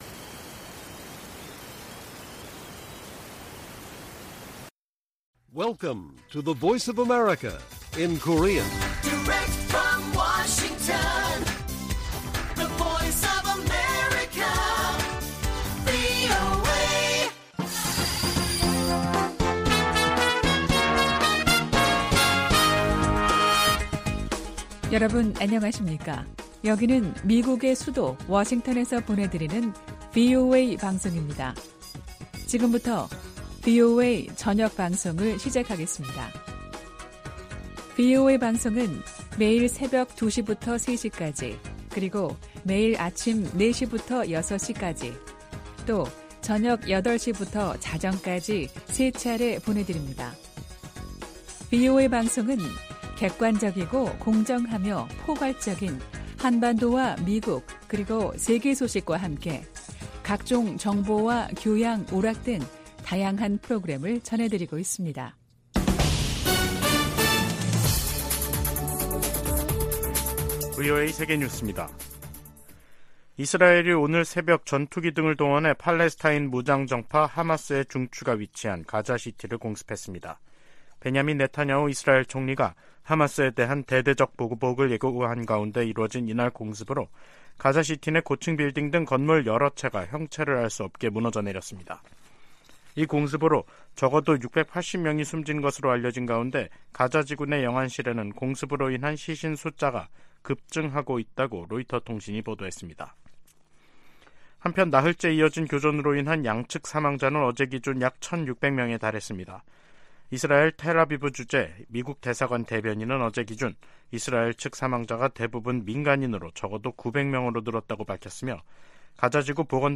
VOA 한국어 간판 뉴스 프로그램 '뉴스 투데이', 2023년 10월 10일 1부 방송입니다. 신원식 한국 국방부 장관이 하마스에 공격당한 이스라엘을 교훈 삼아 대북 정찰 감시 능력을 제한한 9.19 남북 군사합의의 효력 정지를 추진하겠다고 밝혔습니다. 북한이 하마스의 이스라엘 기습에서 군사 전략적 방안을 터득할 수 있다고 미국의 전문가들이 진단했습니다.